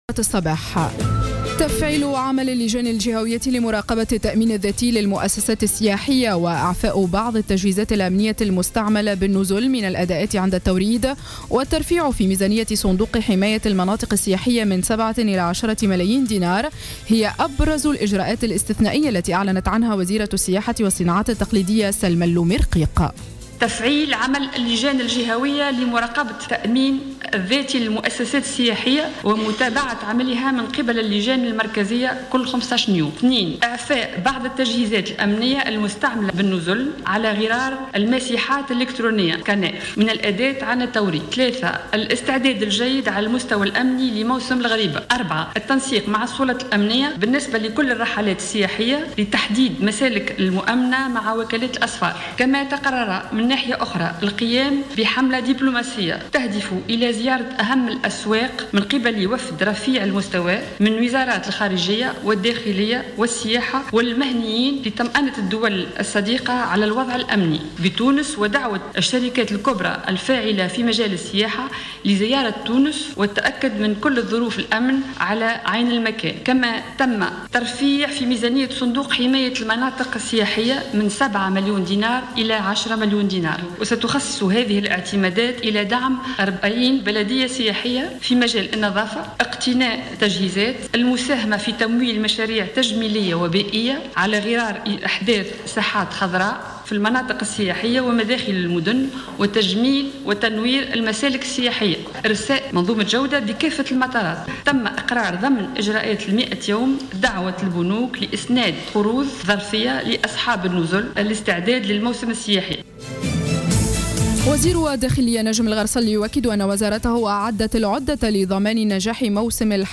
نشرة أخبار السابعة صباحا ليوم السبت 11 أفريل 2015